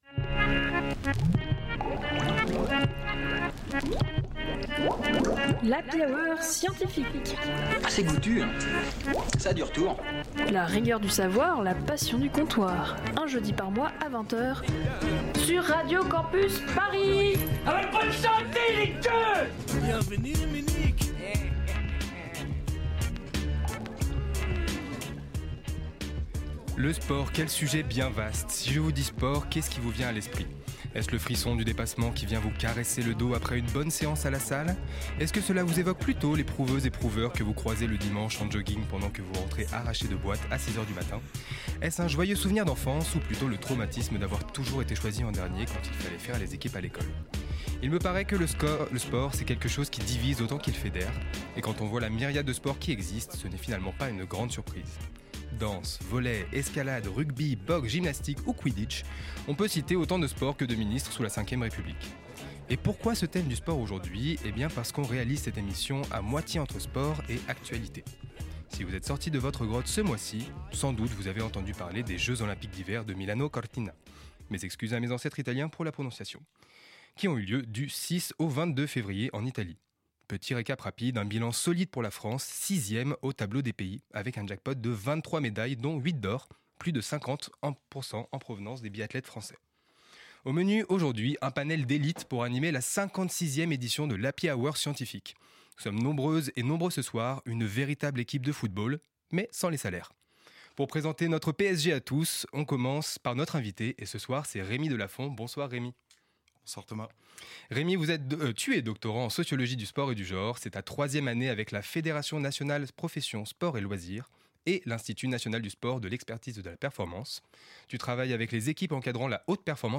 Type Magazine Sciences